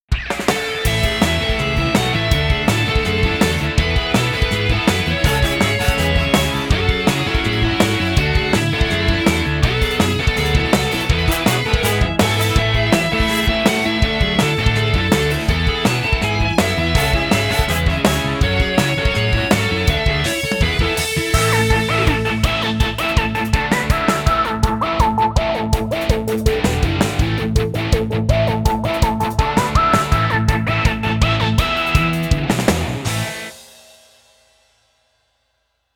かっこいい , エレキギター
OP 疾走感あふれるポップなロックです。